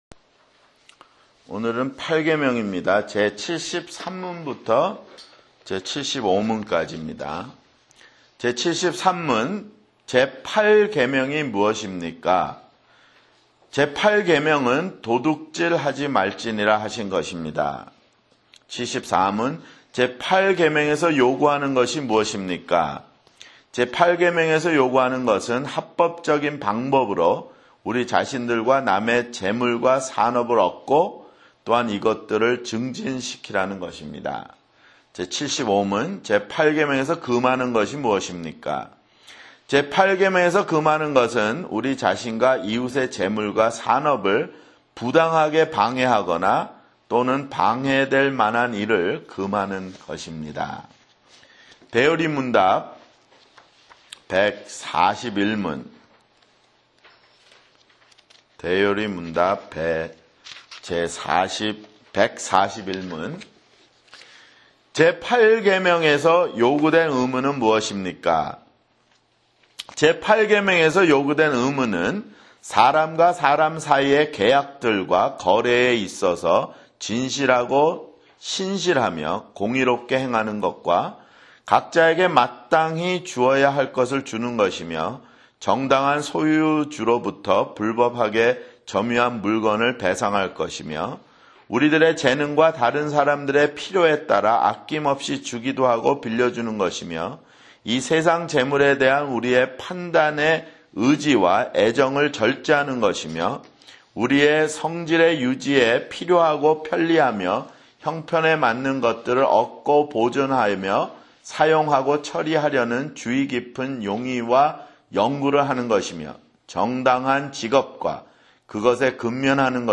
[성경공부] 소요리문답 (62)